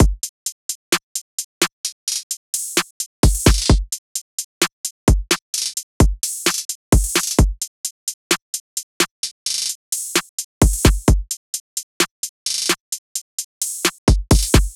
SOUTHSIDE_beat_loop_purple_full_01_130.wav